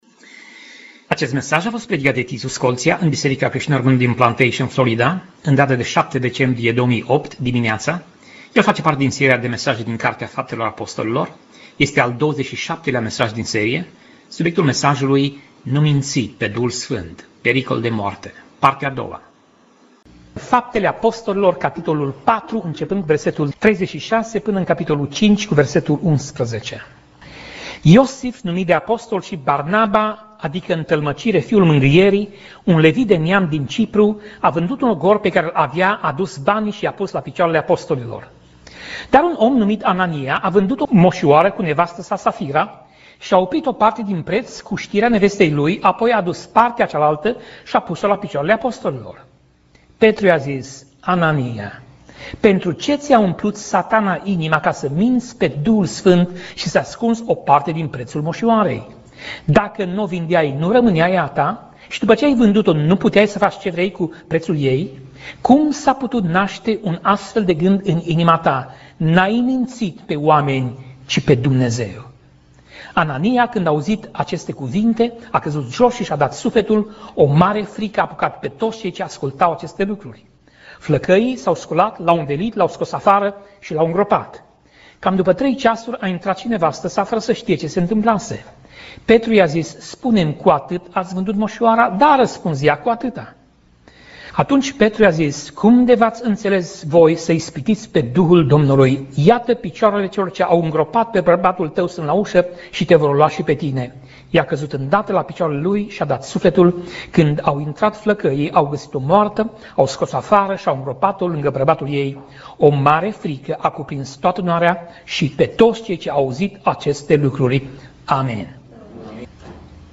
Pasaj Biblie: Faptele Apostolilor 5:1 - Faptele Apostolilor 5:11 Tip Mesaj: Predica